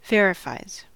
Ääntäminen
Ääntäminen US Haettu sana löytyi näillä lähdekielillä: englanti Verifies on sanan verify yksikön kolmannen persoonan indikatiivin preesens.